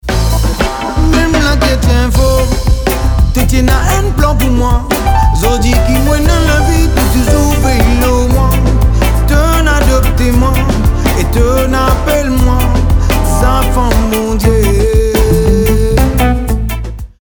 Clavier & Arrangeur
Basse
Guitare
Batterie & Percussions